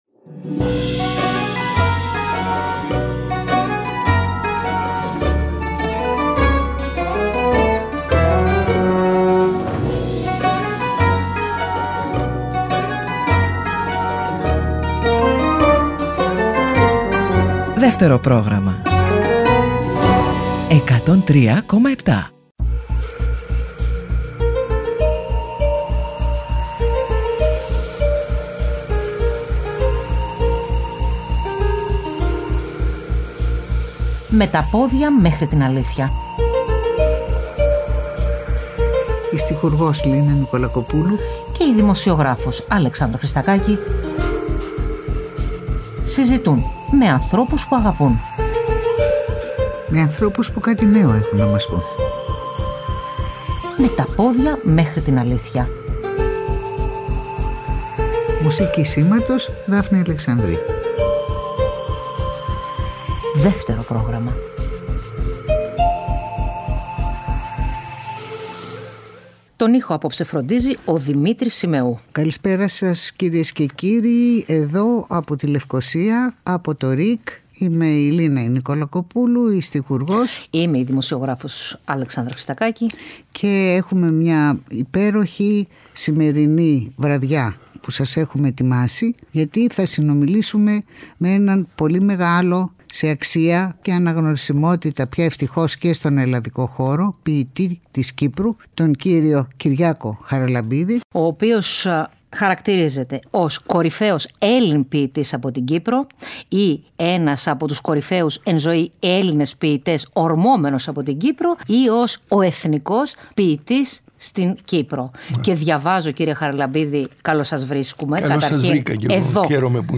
Συζητά στο στούντιο του ΡΙΚ